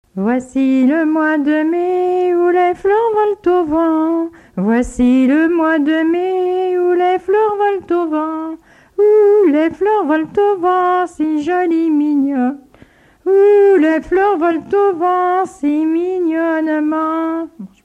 Genre laisse
Chansons traditionnelles et populaires